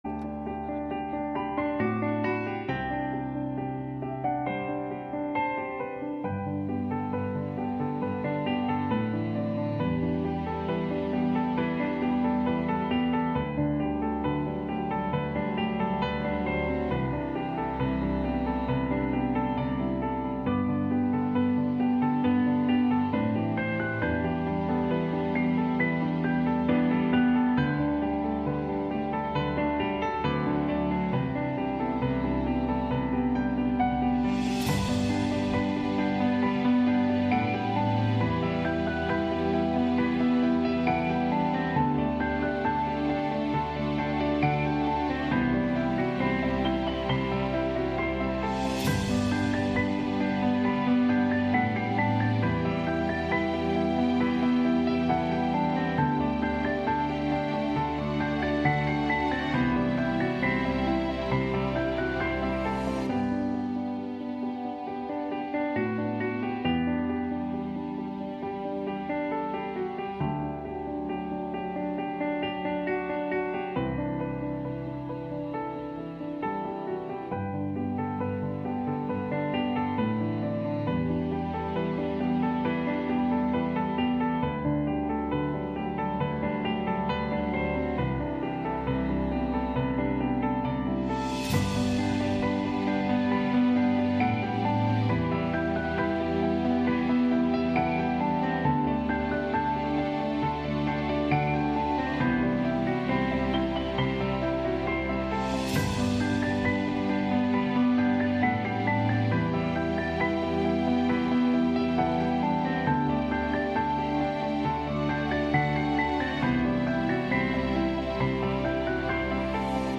We begin our study of the book of Ruth. Ruth’s story reminds us of God’s presence in our everyday lives and His power to bring redemption even from the depths of sorrow. NOTE: The audio file was distorted at the beginning of this broadcast, it does eventually clear up.